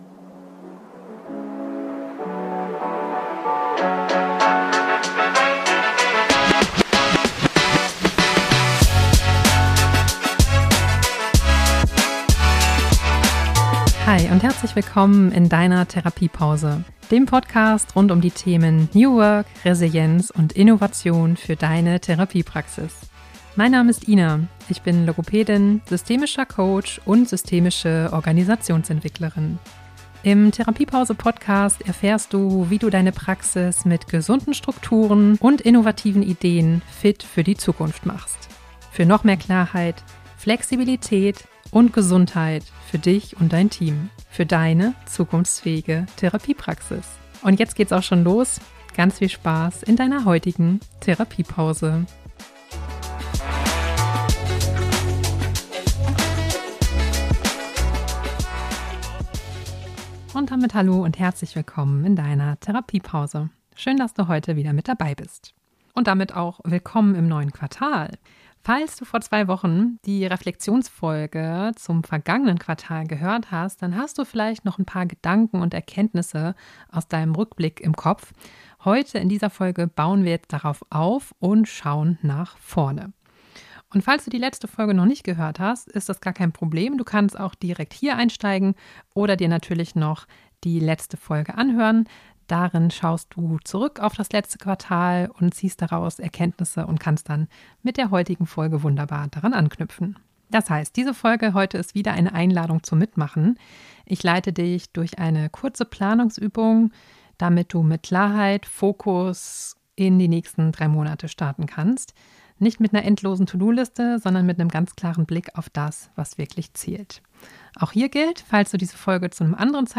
In dieser Folge führe ich dich durch eine angeleitete Planungsübung, mit der du dein neues Quartal bewusst startest - mit echten Pausen zum Nachdenken. Für mehr Fokus, Gelassenheit und Entlastung im Praxisalltag.